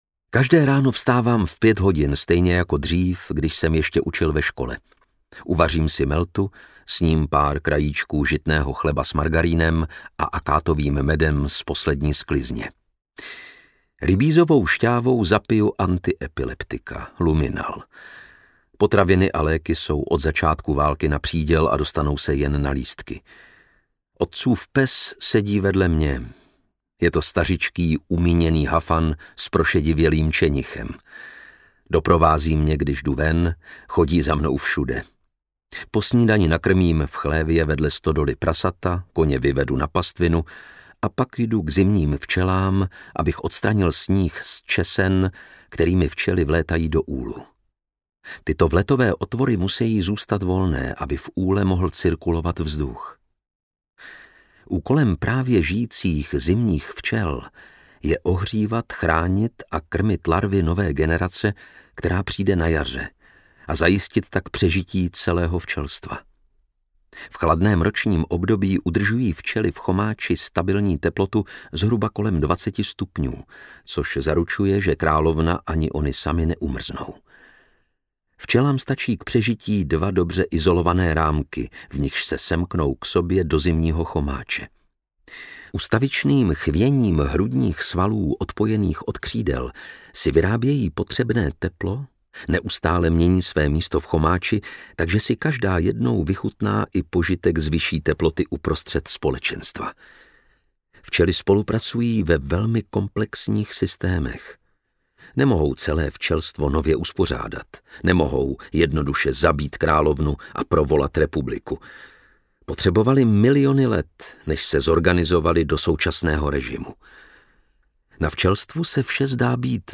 Zimní včely audiokniha
Ukázka z knihy
Čte Lukáš Hlavica.
Vyrobilo studio Soundguru.